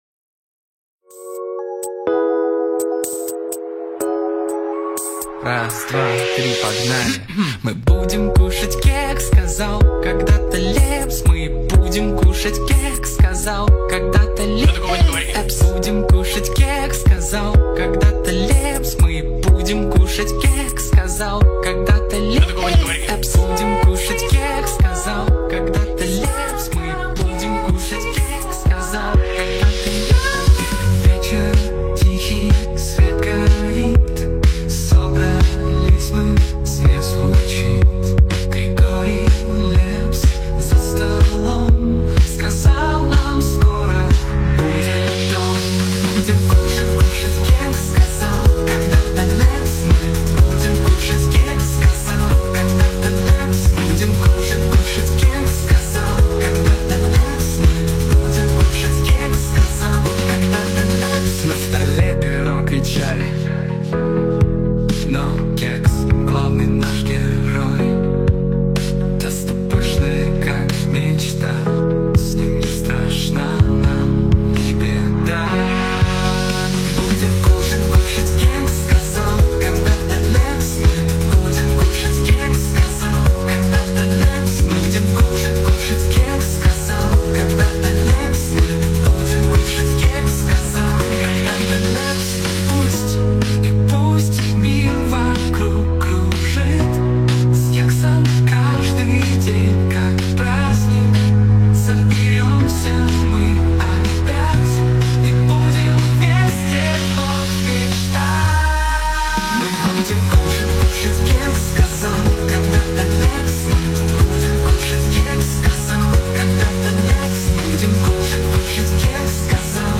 Песня (допетая нейросетью)